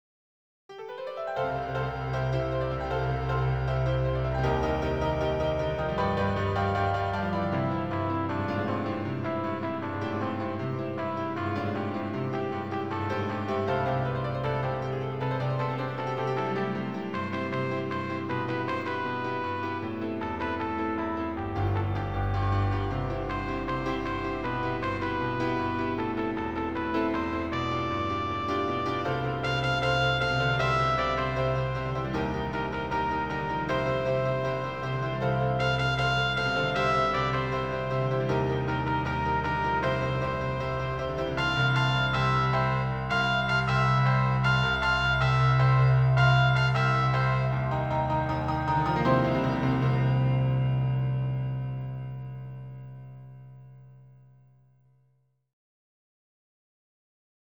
挿入歌１